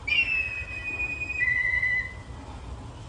黑鸢叫声